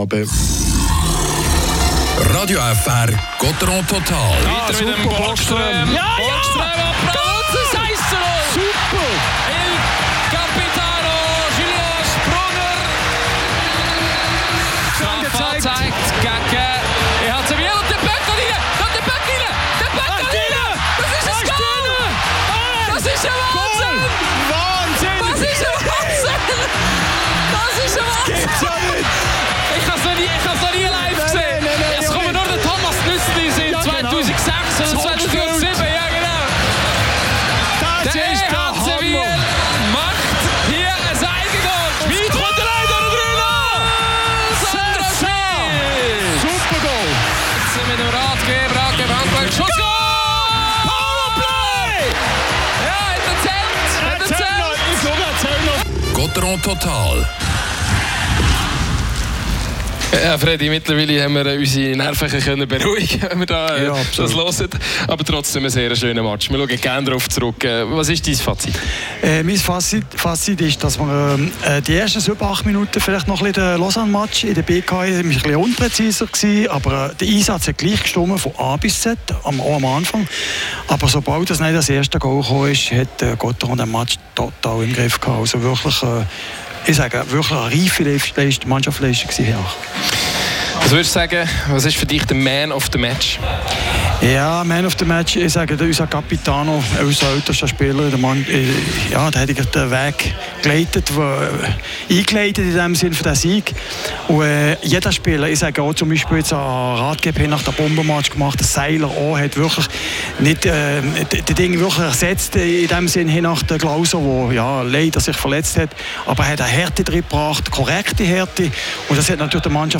Interviews mit Julien Sprunger und Yannick Rathgeb.